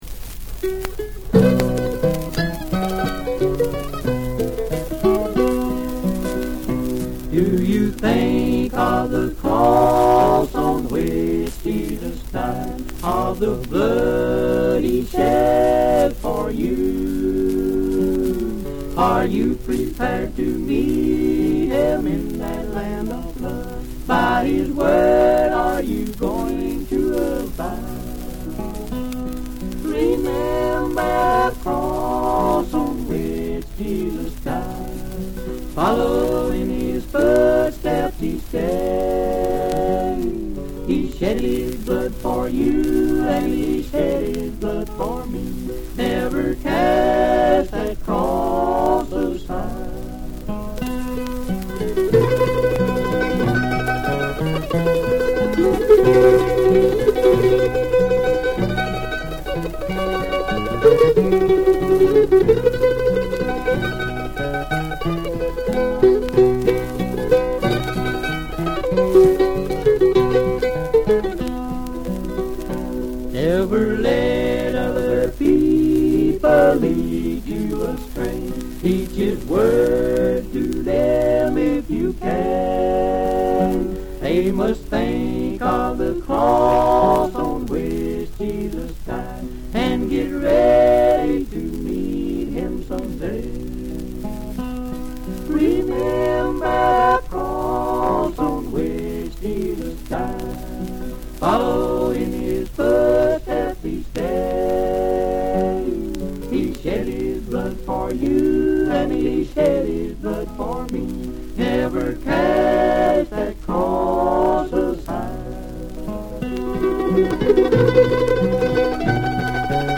Mountain Gospel
- Gospel Quartets